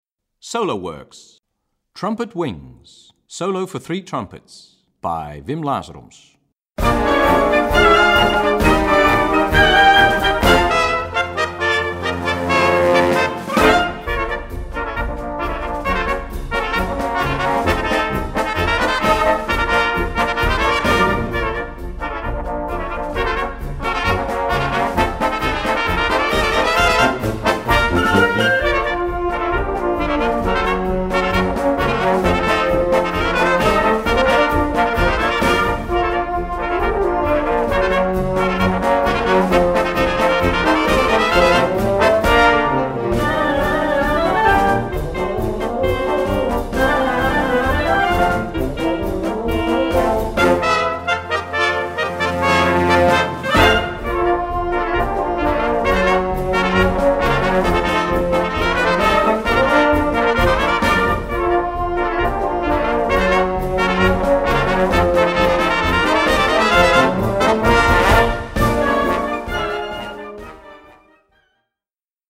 Gattung: Solo für 3 Trompeten und Blasorchester
Besetzung: Blasorchester